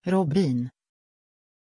Aussprache von Robbin
pronunciation-robbin-sv.mp3